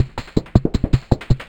Index of /90_sSampleCDs/Spectrasonic Distorted Reality 2/Partition A/03 80-89 BPM